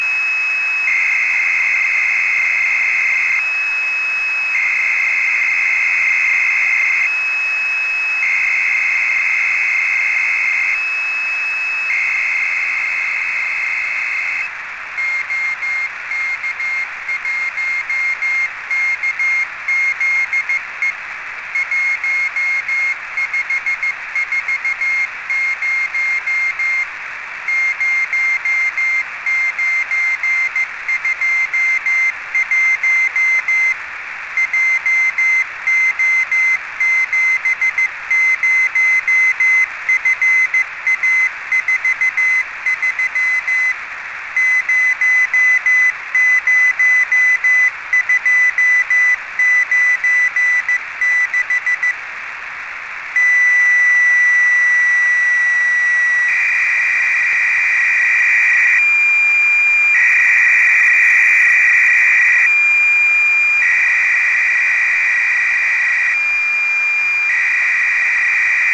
Morse část zprávy se skládala z volacího znaku, souřadnic a lokátoru:
Rychlost asi 20WPM, takže se dalo v klidu stíhat.
Morse byla prokládána radiodálnopisem RRTY s modulační rychlostí 300BD
Díky změnám teploty signál ujížděl, max úchylka až 4kHz.